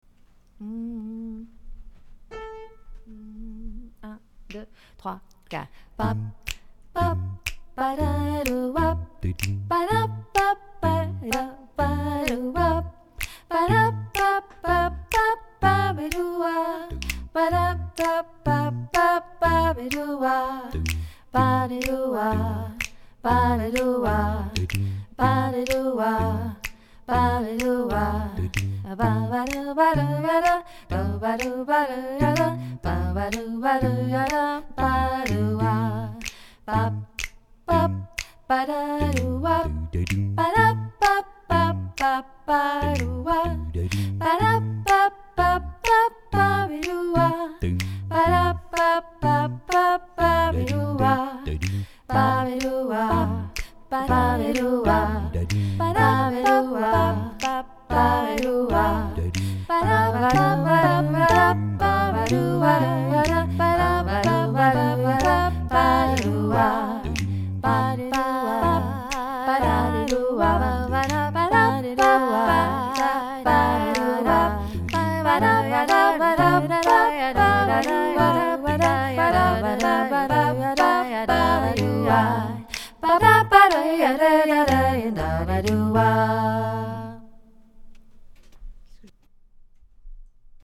Chorale adultes
La première version est la version finale, c'est-à-dire ce que donnera le morceau, une fois que nous l'aurons tous appris. les autres versions sont les voix séparées qui vous concernent : la voix que vous devez apprendre est mise en avant, et vous entendrez le reste en fond sonore.